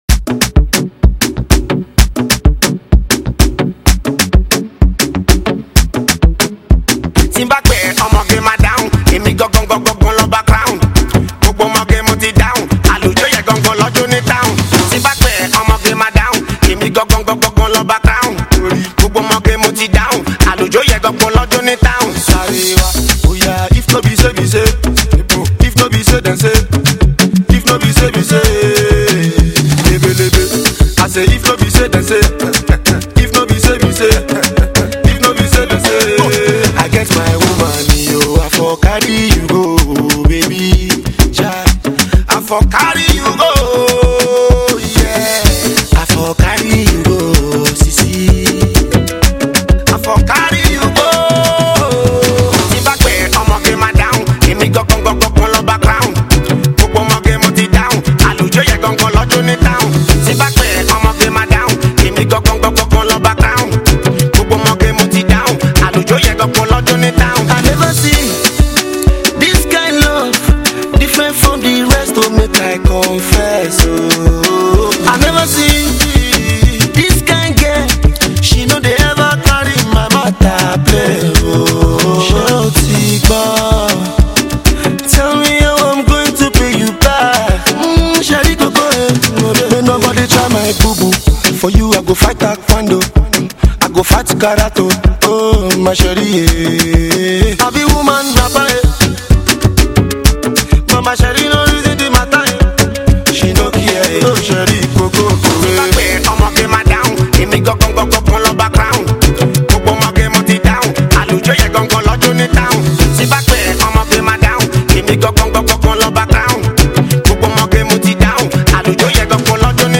banger